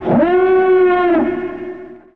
An emergency siren was a noise-making system that produced a penetrating warning sound.
A common type of signal horn, found notably on Mustafar
SignalHorn.ogg